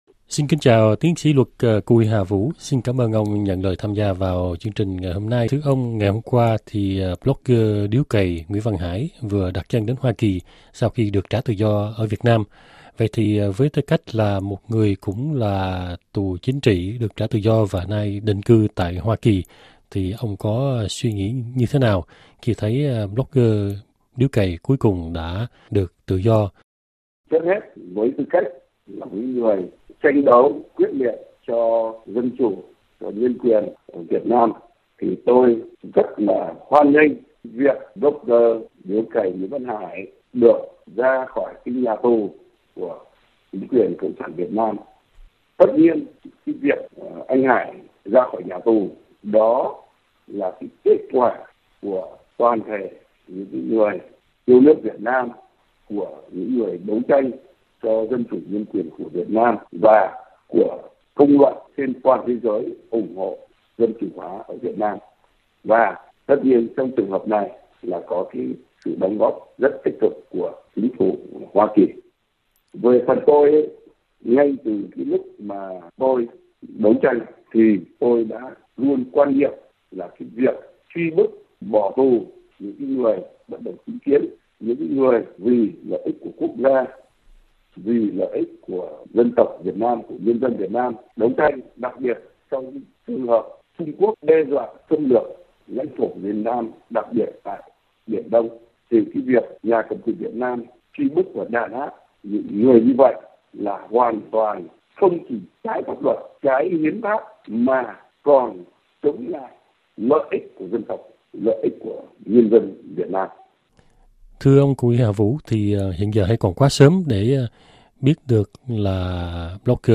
Tuy hoàn cảnh đi sang Hoa Kỳ của hai người có thể là khác nhau, nhưng dù ở nước ngoài, họ vẫn có thể tiếp tục đấu tranh cho dân chủ ở Việt Nam. Đó là điều mà ông Cù Huy Hà Vũ cho biết khi trả lời phỏng vấn RFI Việt ngữ hôm nay